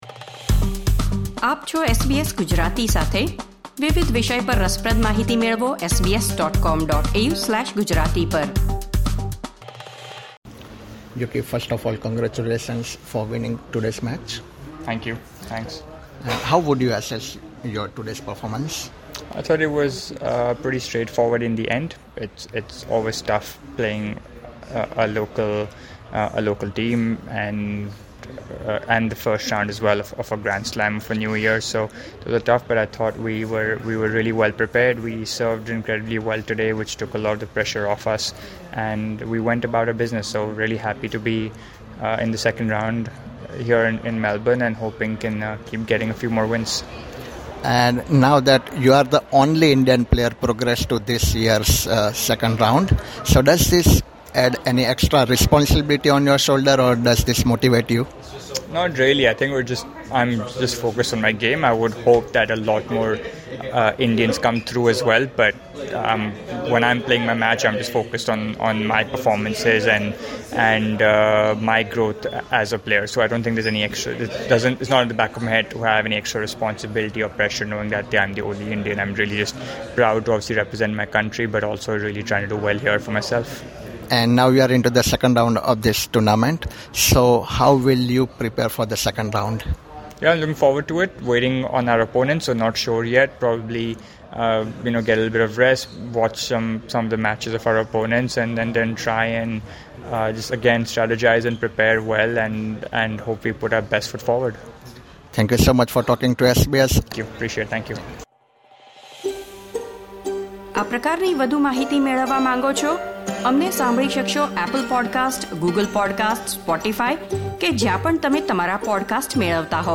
Indian tennis player Yuki Bhambri, along with his Swedish partner Andre Goransson, progressed to the second round of the Australian Open men’s doubles competition after defeating Australia’s James Duckworth and Cruz Hewitt in straight sets, 6–3, 6–4. Yuki spoke with SBS about his performance and his upcoming matches in the tournament.